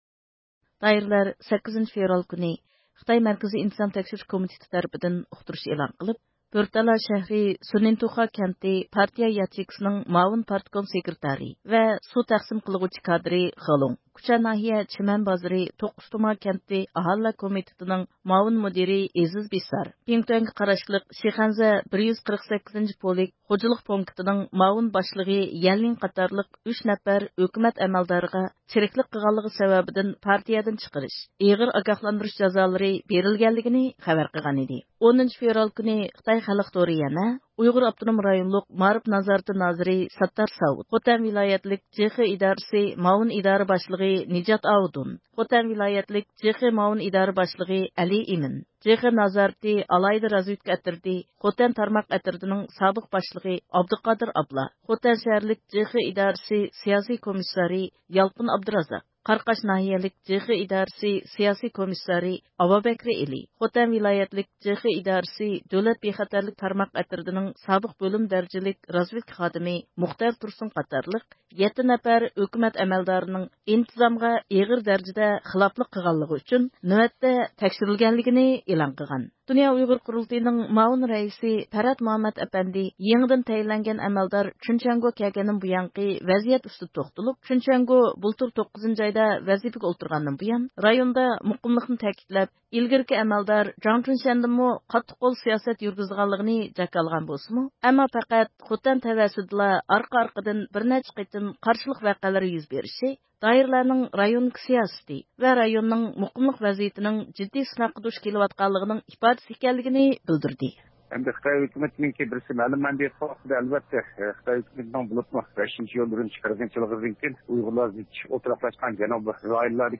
ئەركىن ئاسىيا رادىئوسى زىيارىتىنى قوبۇل قىلىپ، ئۆز قاراشلىرىنى بايان قىلغان.